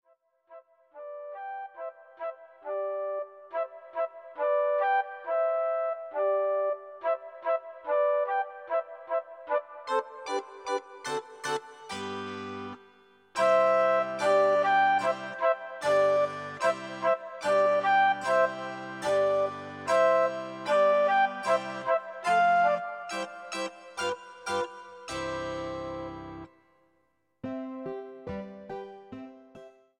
Dášeňka čili život štěněte audiokniha
Audiokniha Dášeňka čili život štěněte, kterou napsal Karel Čapek a kterou čte Petr Štěpánek.
Ukázka z knihy